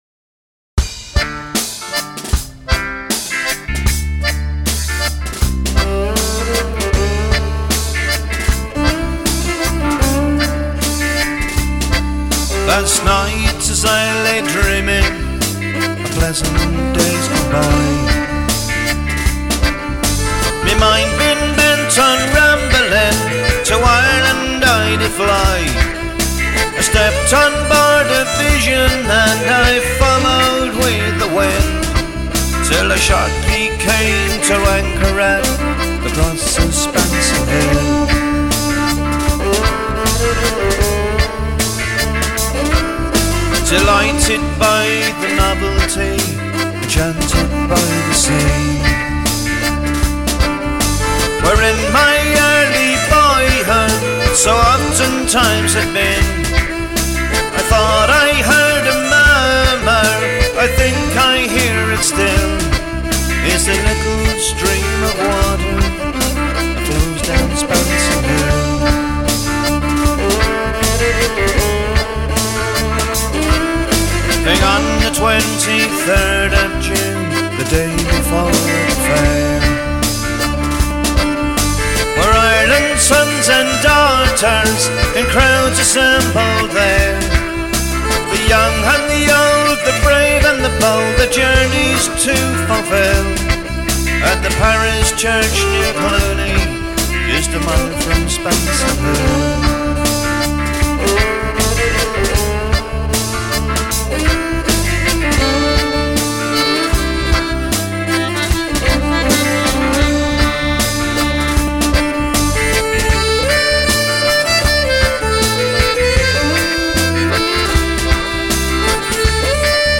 Unplugged
Pop